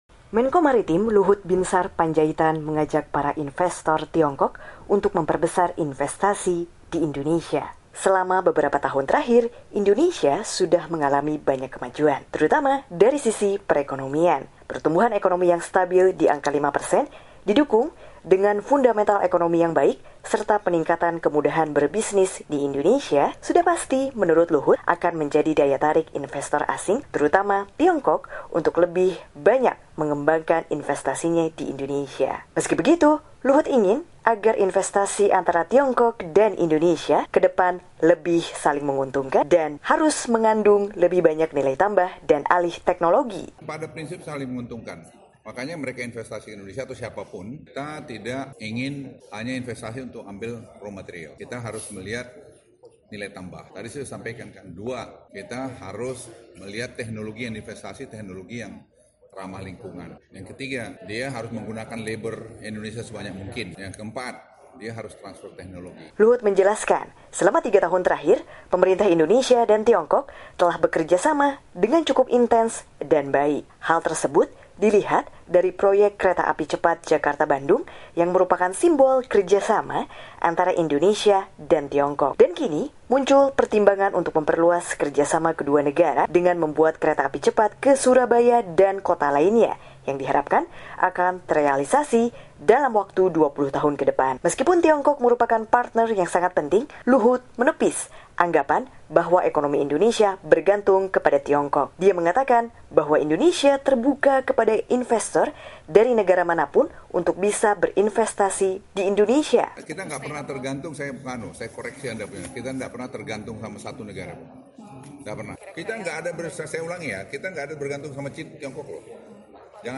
Hal tersebut disampaikannya dalam acara Seminar Lima Tahun Kerjasama Strategis dan Komprehensif Indonesia-China di Hotel Mulia, Senayan, Jakarta, Selasa (27/11).